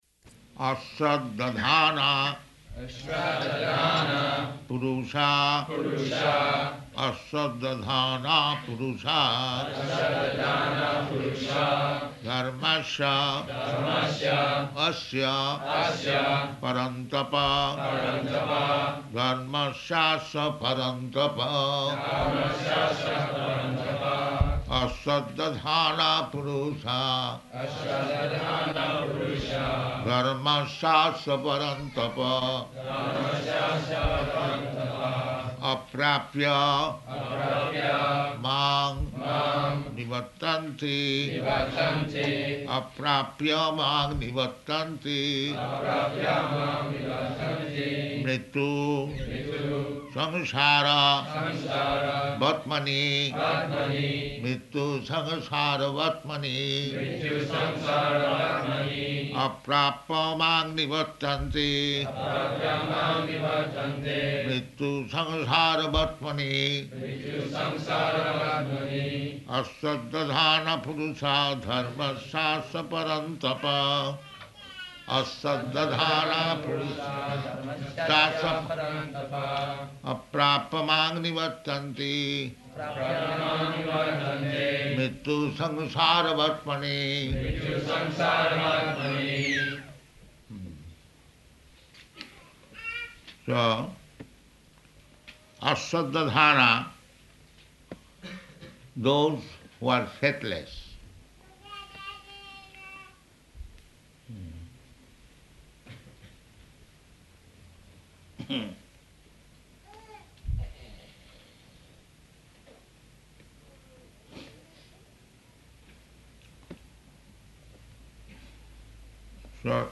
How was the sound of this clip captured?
Location: Melbourne